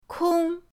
kong1.mp3